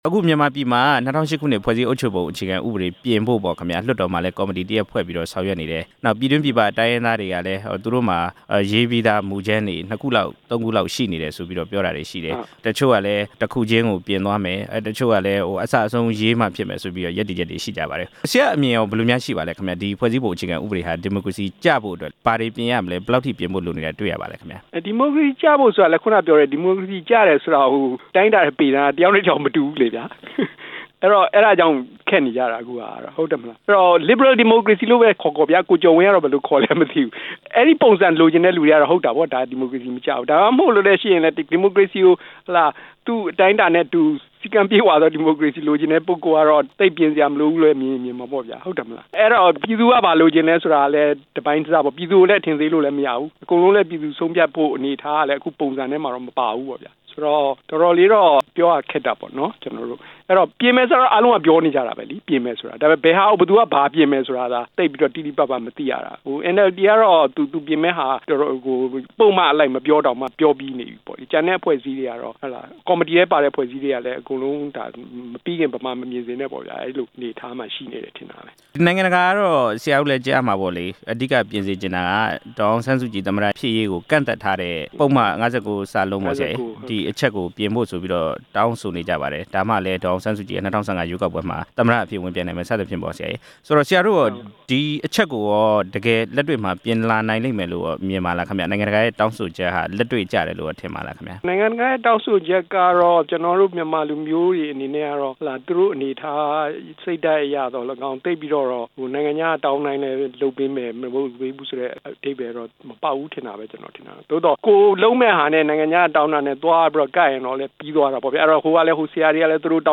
၂၀၀၈ အခြေခံဥပဒေပြင်ဆင်ရေး မြန်မာပညာရှင်နဲ့ ဆက်သွယ်မေးမြန်းချက်